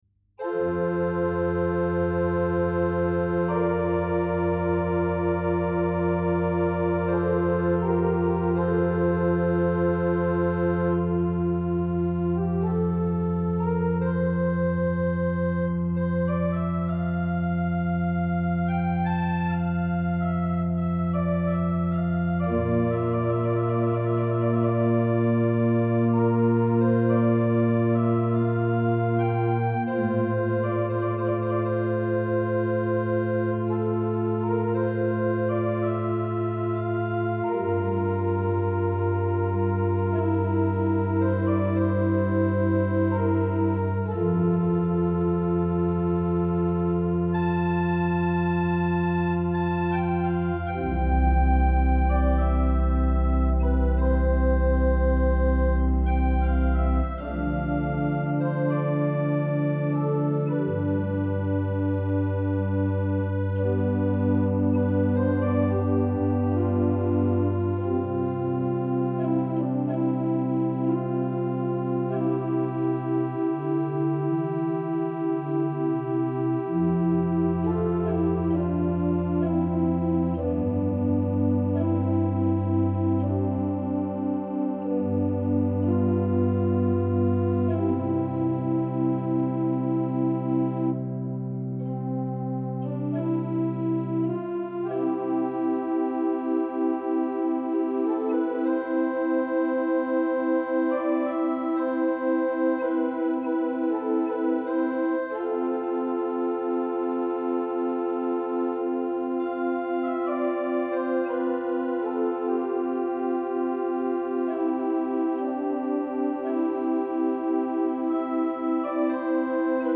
No additional effect processing have been added to the recording. The tail of release is the original of the samples. All traks were recorded with the original temperament of the organ with the exeption of tracks by J.S.Bach that were recorded with equal temperament..
Principale + Voce Umana